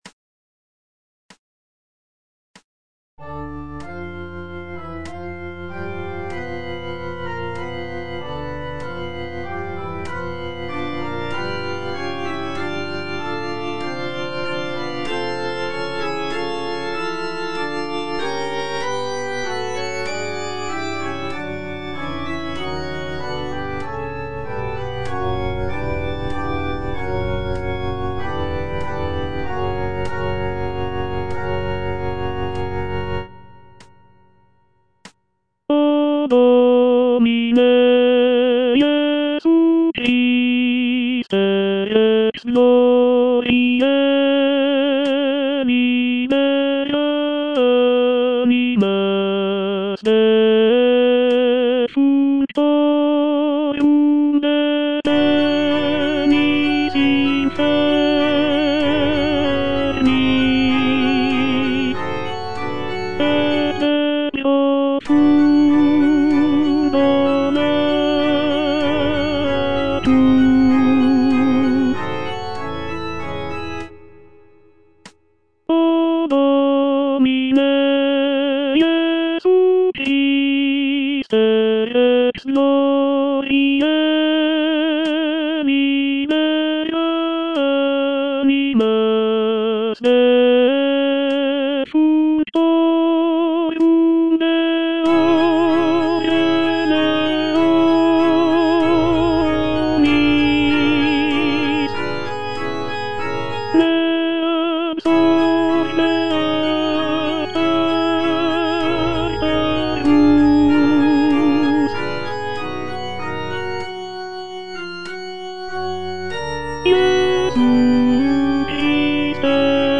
G. FAURÉ - REQUIEM OP.48 (VERSION WITH A SMALLER ORCHESTRA) Offertoire - Tenor (Voice with metronome) Ads stop:
This version features a reduced orchestra with only a few instrumental sections, giving the work a more chamber-like quality.